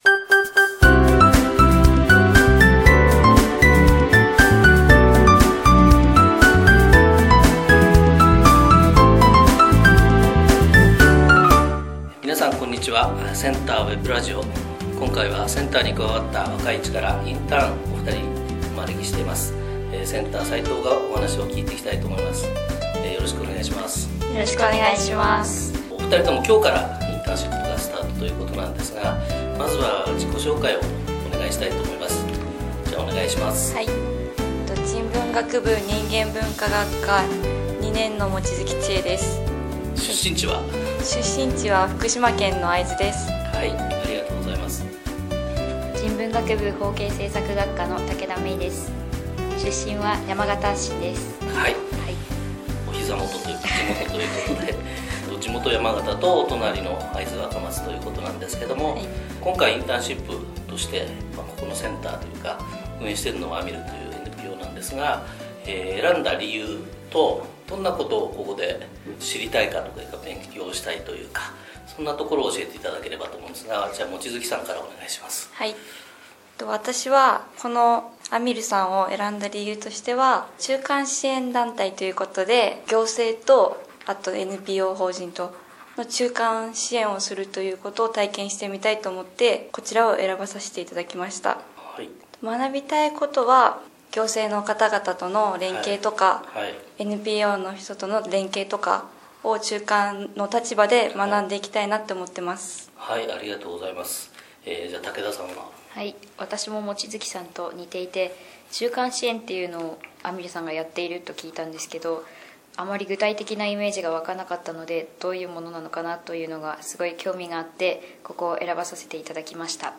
○話し手：インターン生